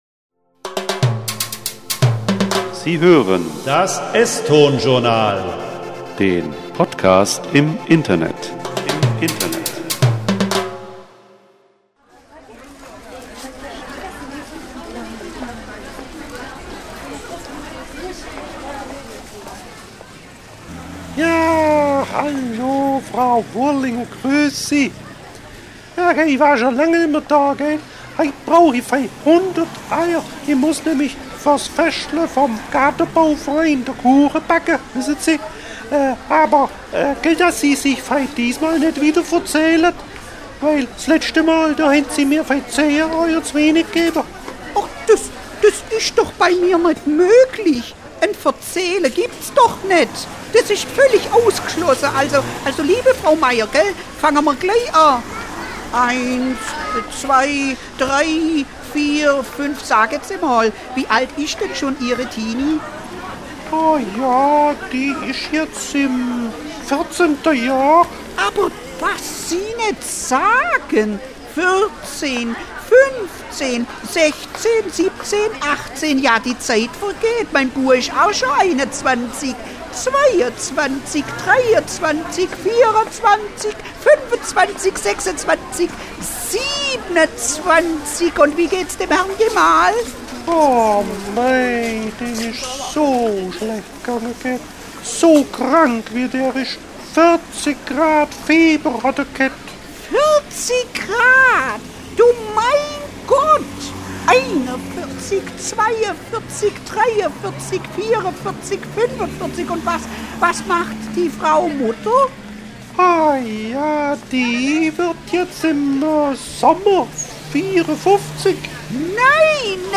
ESTON-Studio, Krumbach (Schwaben).